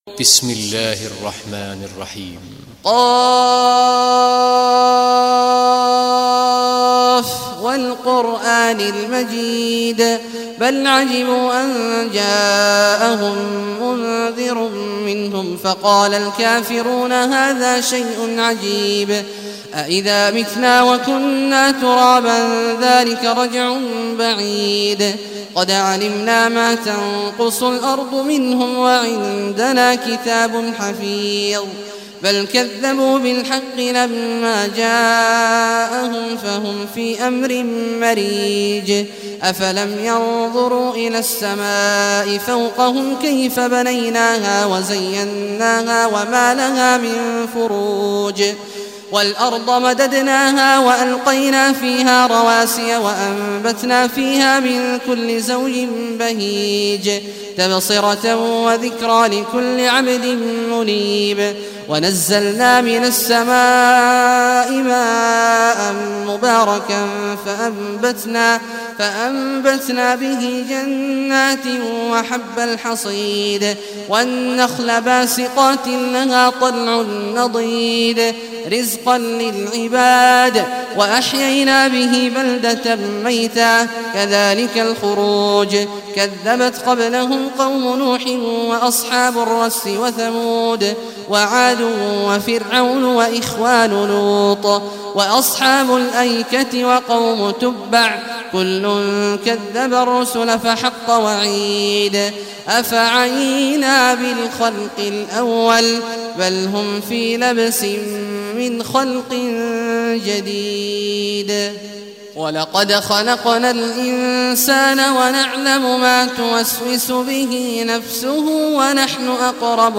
Surah Qaf Recitation by Sheikh Awad al Juhany
Surah Qaf, listen or play online mp3 tilawat / recitation in Arabic in the beautiful voice of Sheikh Abdullah Awad al Juhany.